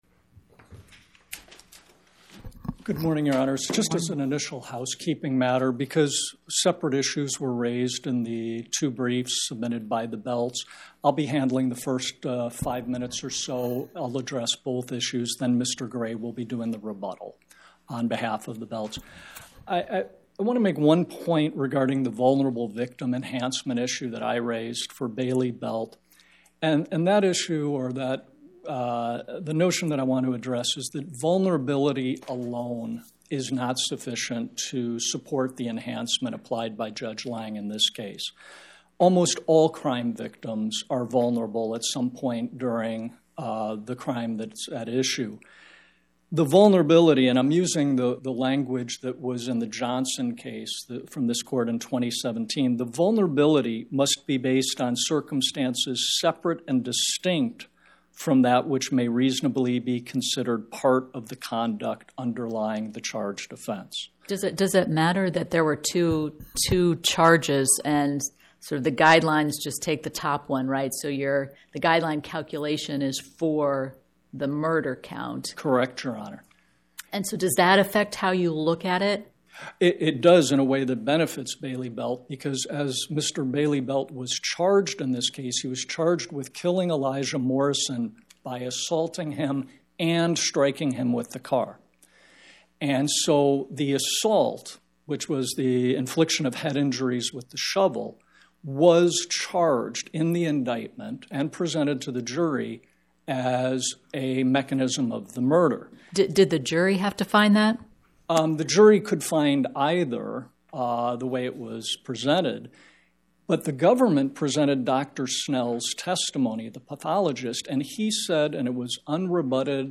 Oral argument argued before the Eighth Circuit U.S. Court of Appeals on or about 10/22/2025